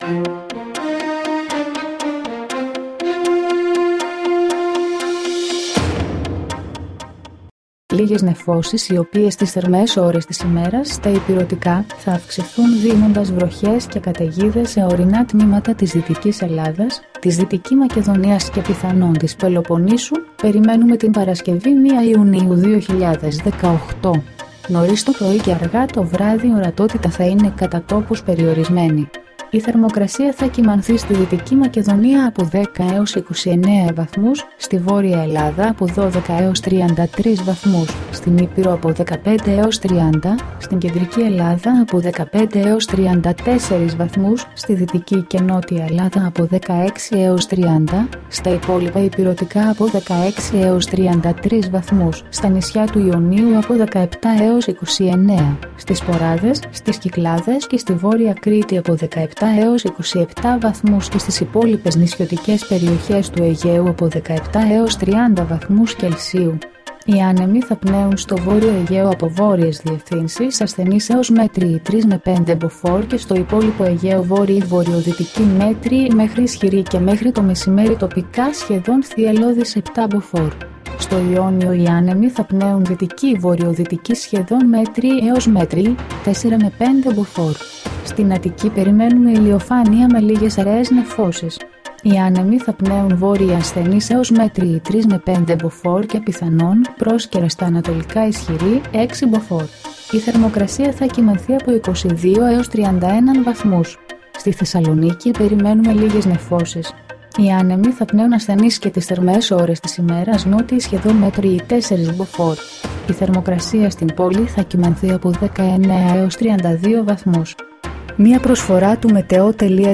dailyforecasta.mp3